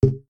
snd_hit1.ogg